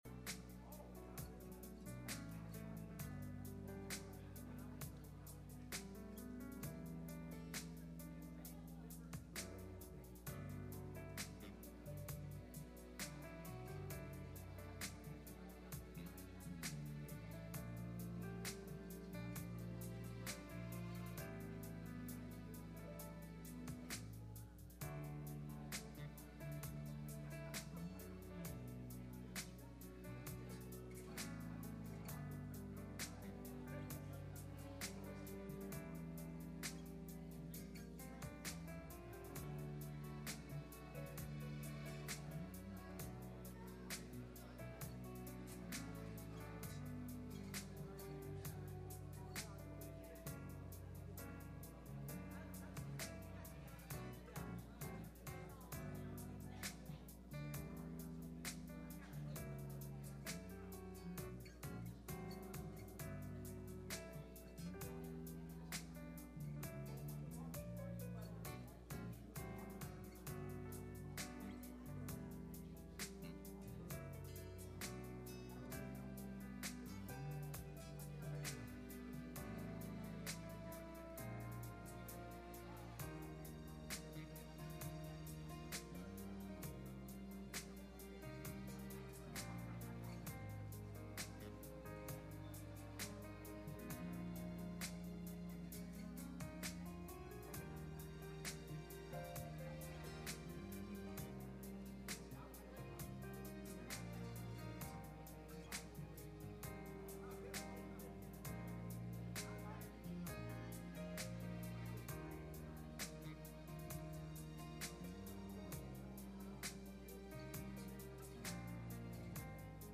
Message Service Type: Sunday Morning https